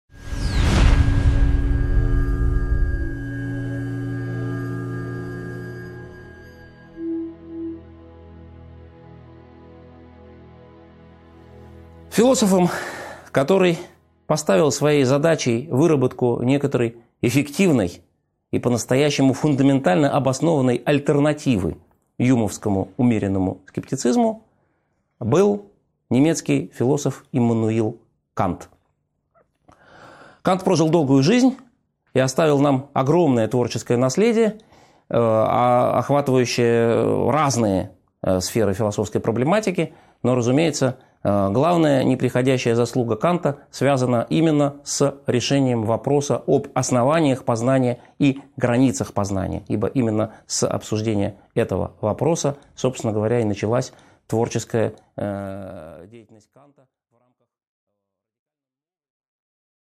Аудиокнига 10.5 Программа трансцендентализма | Библиотека аудиокниг